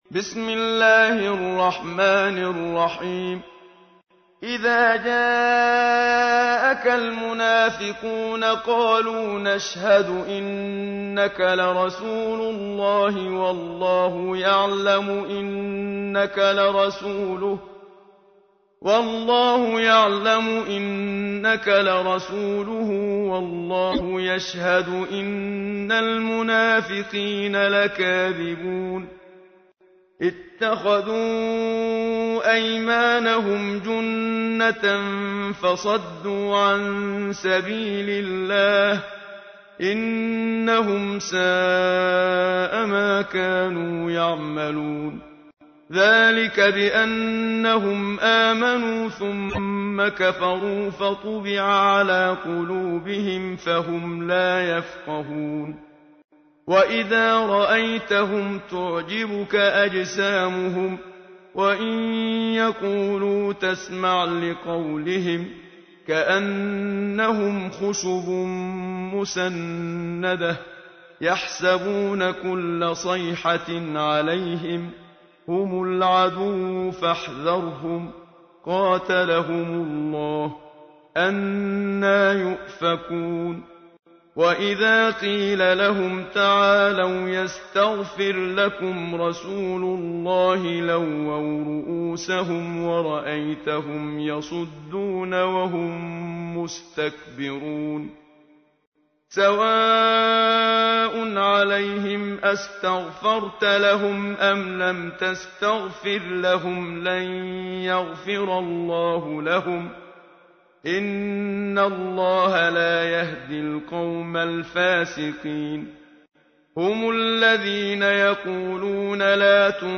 تحميل : 63. سورة المنافقون / القارئ محمد صديق المنشاوي / القرآن الكريم / موقع يا حسين